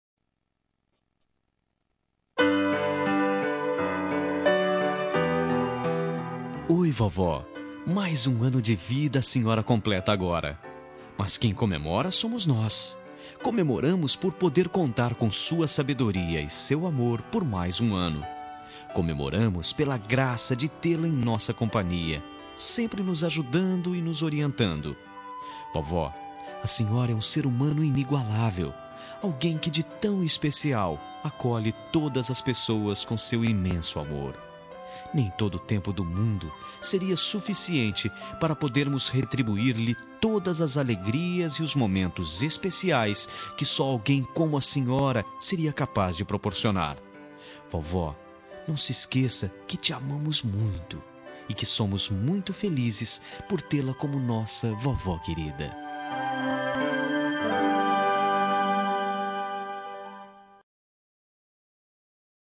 Telemensagem de Aniversário de Avó – Voz Masculina – Cód: 2073